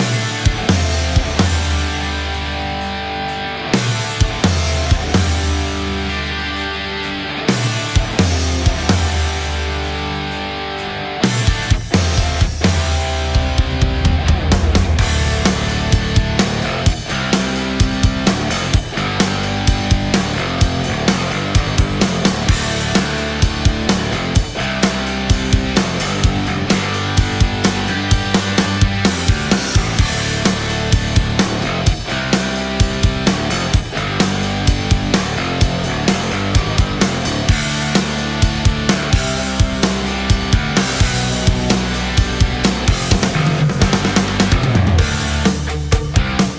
فایل بکینگ ترک از طریق